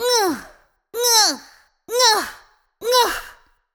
yell-ohh-64bpm-1beat.ogg